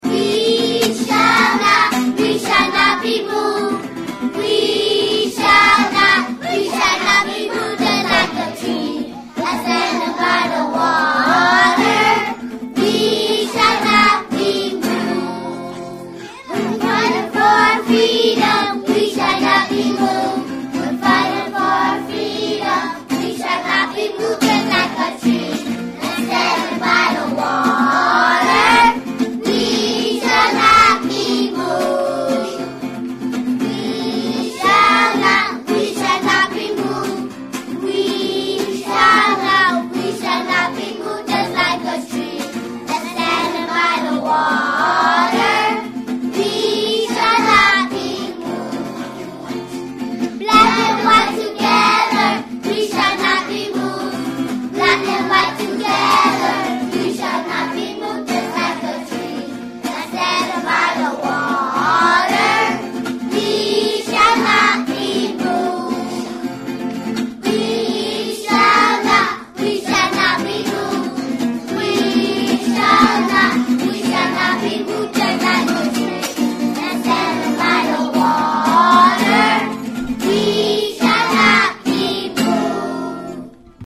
We Shall Not  Be Moved - as sung by Kindergarten Class 010 on this MP3.